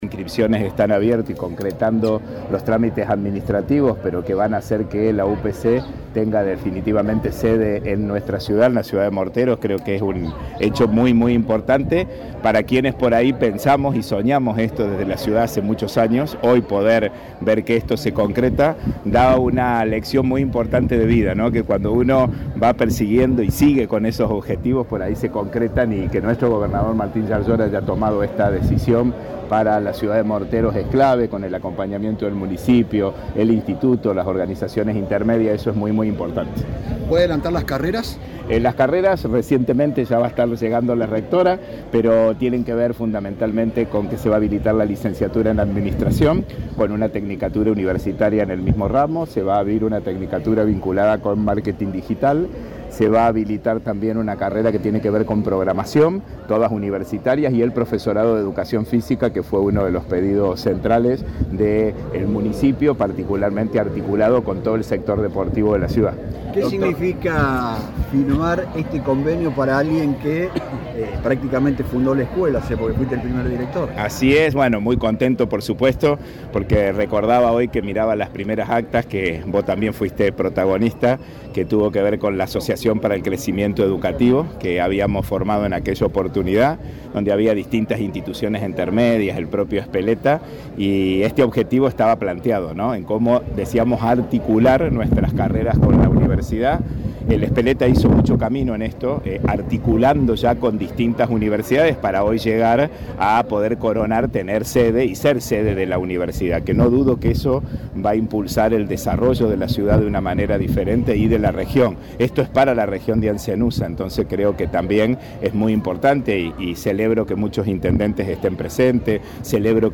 En la mañana del viernes en la Biblioteca Popular Cultura y Progreso de la ciudad de Morteros, las autoridades firmaron la transferencia del Colegio Ezpeleta a la Universidad Provincial.